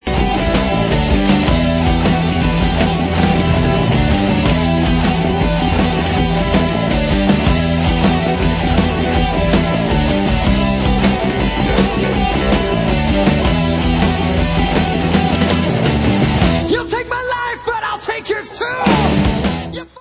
sledovat novinky v oddělení Heavy Metal
Rock